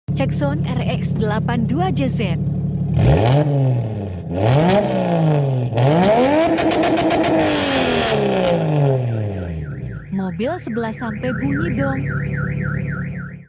Engine Swap 2jz Single Turbo Turbo GT 35